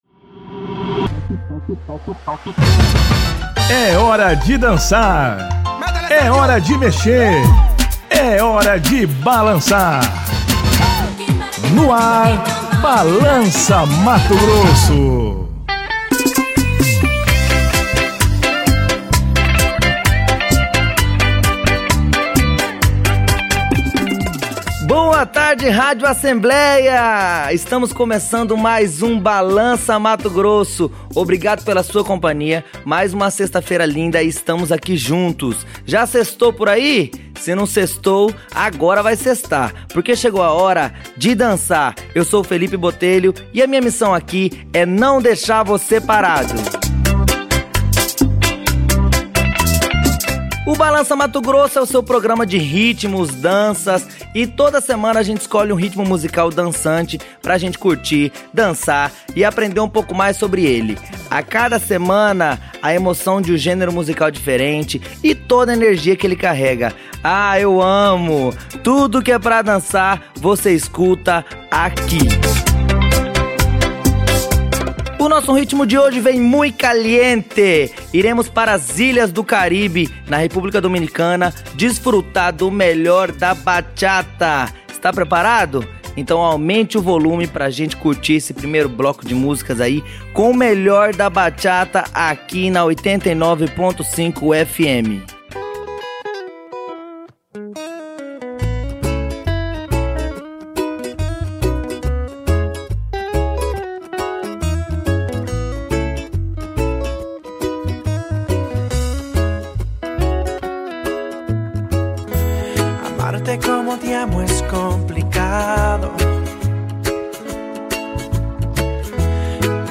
Balança Mato Grosso: Bachata